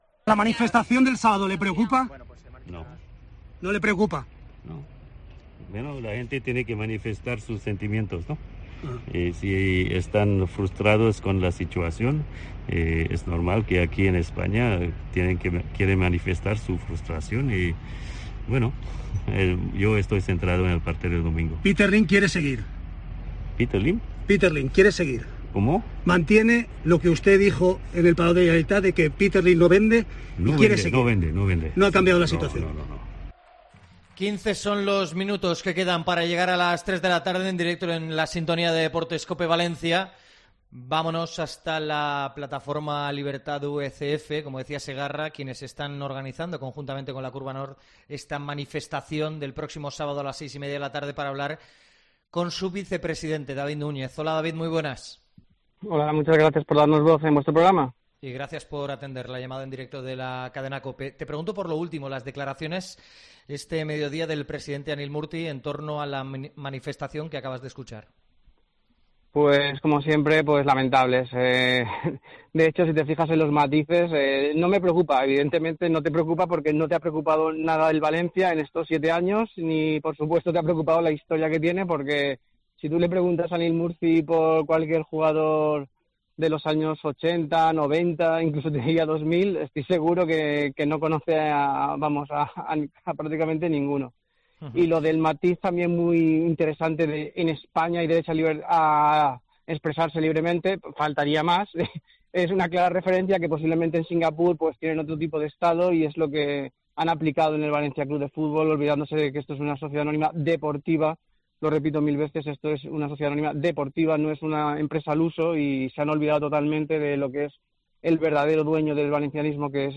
AUDIO. Entrevista